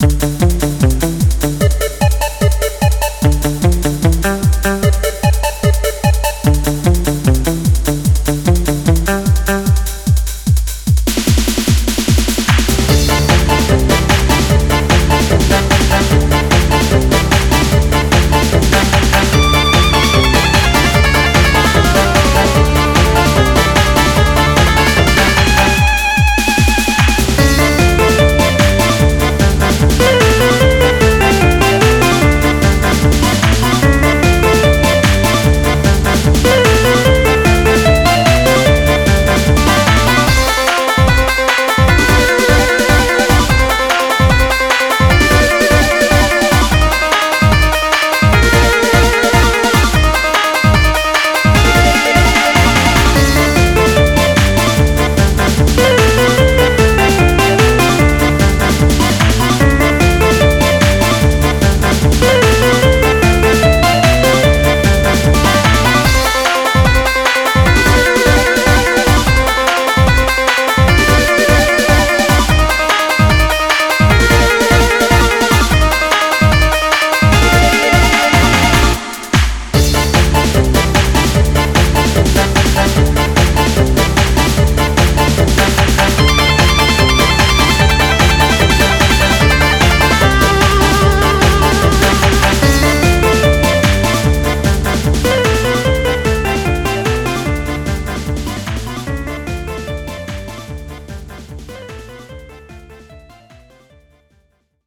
BPM149